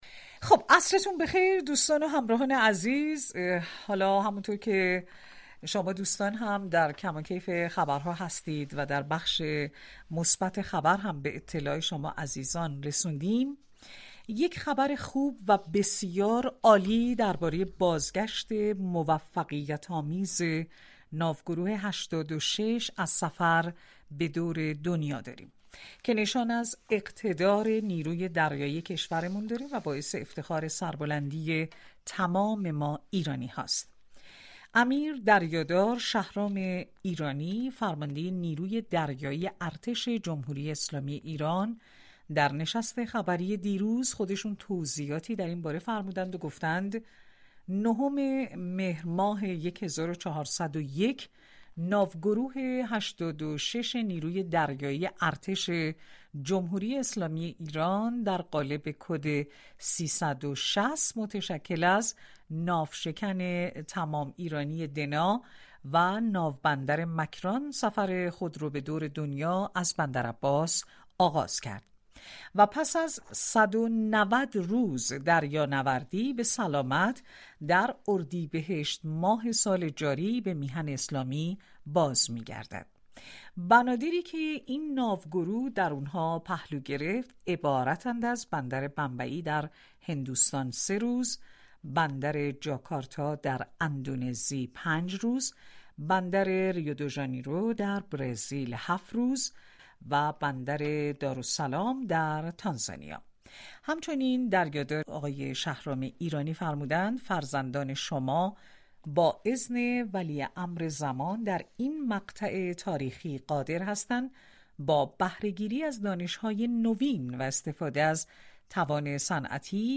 جانشین عملیات نیروی دریایی راهبردی ارتش جمهوری اسلامی ایران در گفتگو تلفنی به تشریح ماموریت موفقیت آمیز دریانوردی به دور كره زمین با ناوشكن دنا پرداخت.